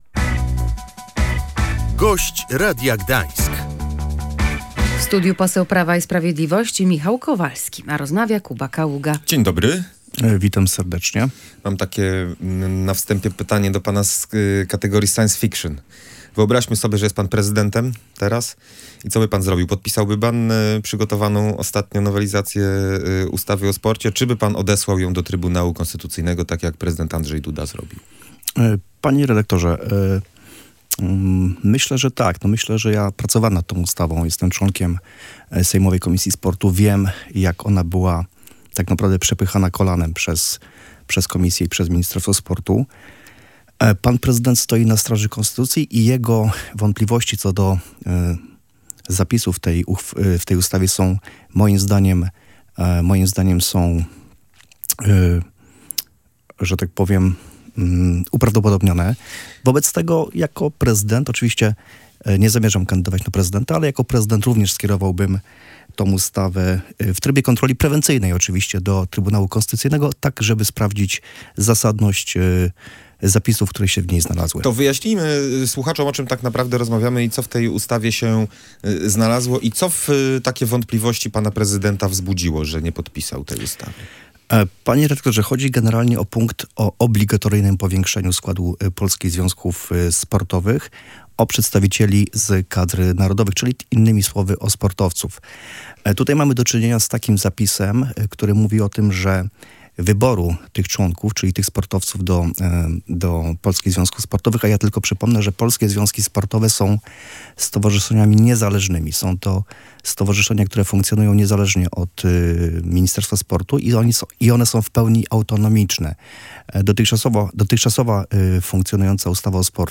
Wątpliwości prezydenta RP dotyczące nowelizacji ustawy o sporcie są uzasadnione - powiedział poseł Prawa i Sprawiedliwości Michał Kowalski w audycji